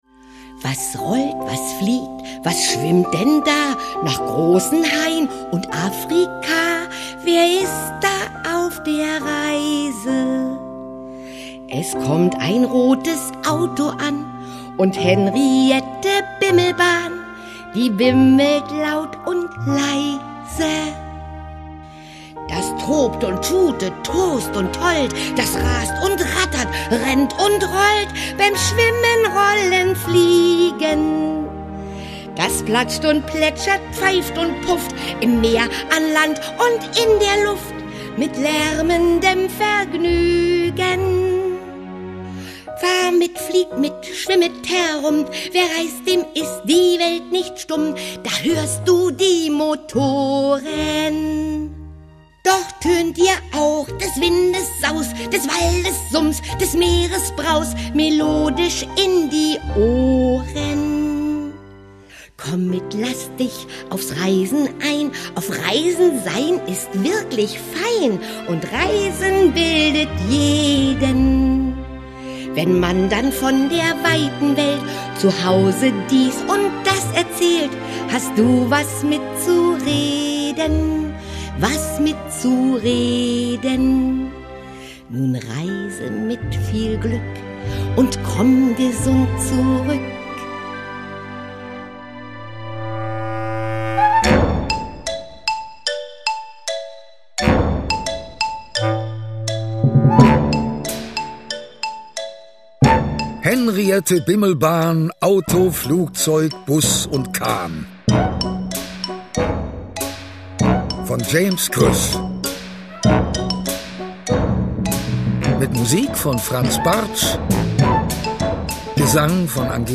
Szenische Lesung mit Musik mit Uwe Friedrichsen und Angelika Mann (1 CD)
Uwe Friedrichsen, Angelika Mann (Sprecher)
Der Hörbuch-Klassiker, gelesen von Uwe Friedrichsen, lädt mit viel Musik zum Immer-wieder-Hören, Singen, Mitsprechen und Weiterreimen ein.